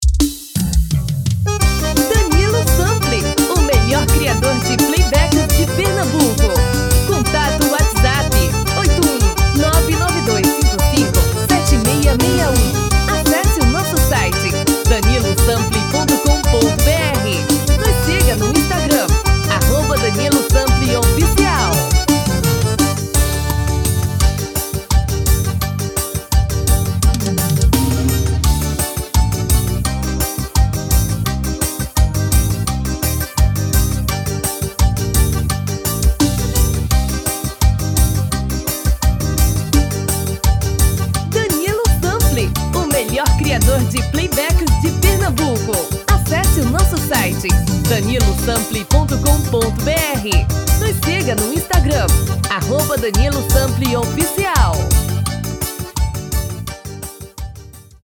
DEMO 1: tom original DEMO 2: tom feminino